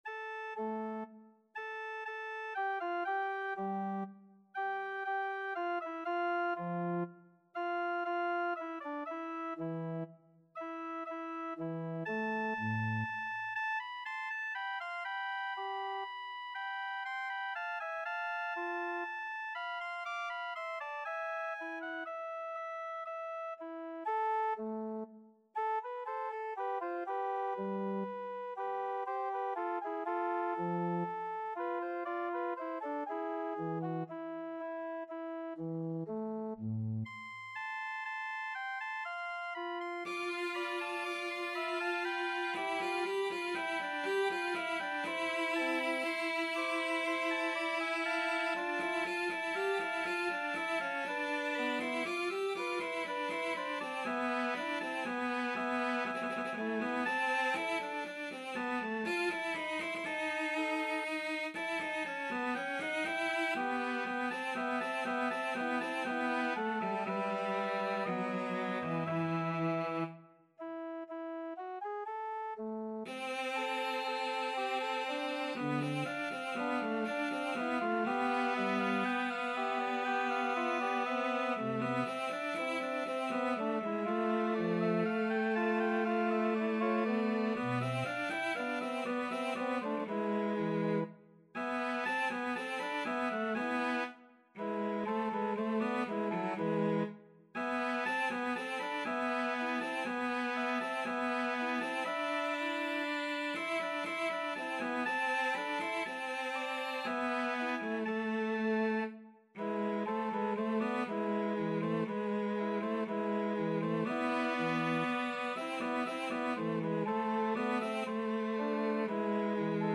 Cello
3/4 (View more 3/4 Music)
A minor (Sounding Pitch) (View more A minor Music for Cello )
Adagio
Classical (View more Classical Cello Music)